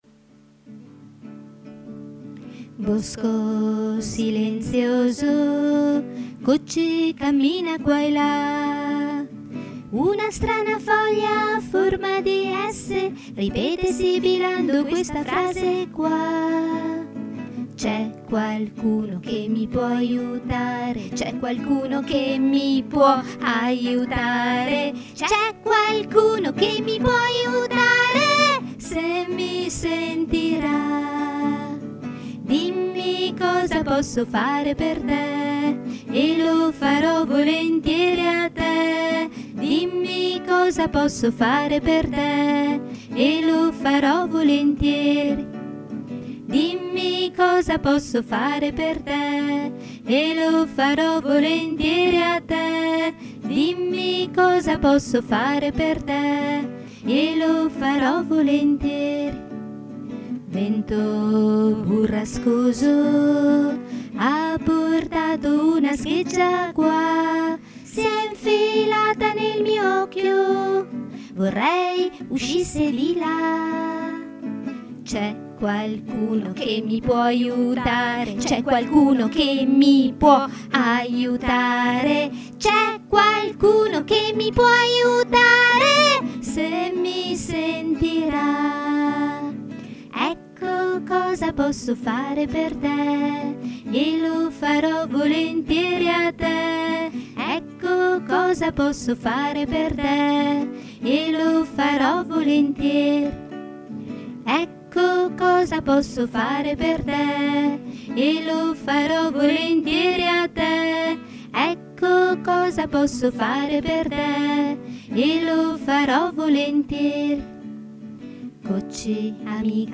Dall' incontro Bosco di Ancona 2005 re sol la re Bosco silenzioso Cocci cammina qua e l� re7 sol una strana foglia a forma di esse si- re la ripete sibilando questa frase qua re sol RIT.